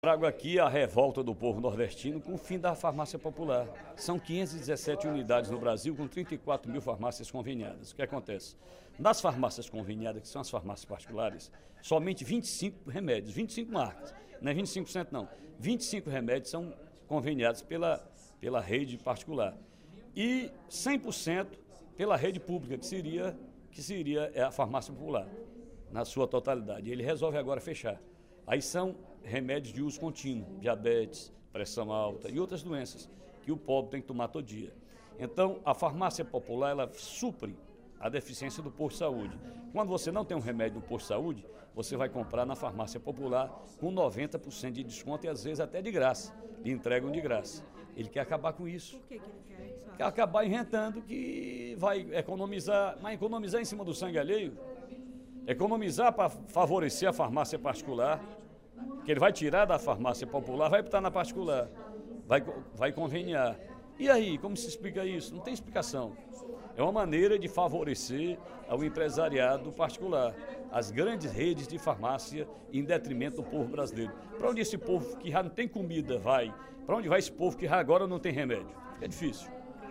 O deputado Ferreira Aragão (PDT) criticou, nesta terça-feira (25/04), durante o primeiro expediente da sessão plenária, a proposta do Governo Federal de acabar com as Farmácias Populares, o que considera a maior judiação já feita no País.